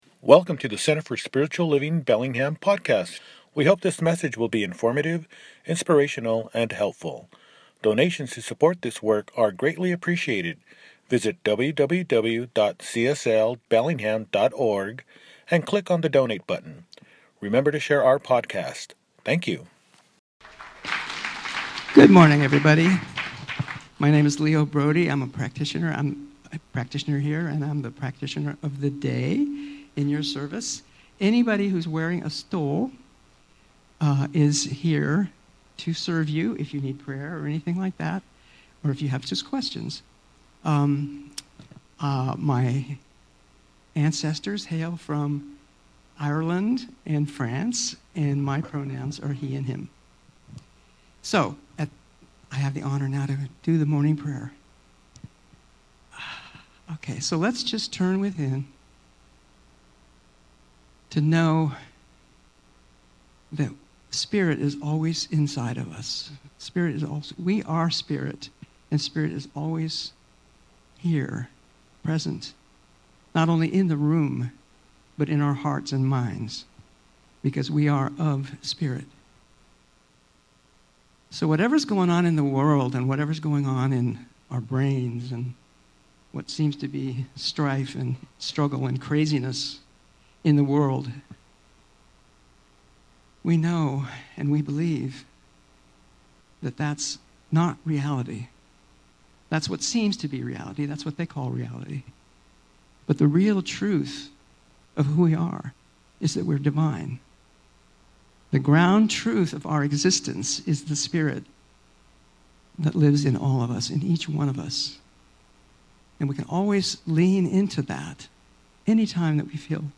When You Get the Choice, Dance – Celebration Service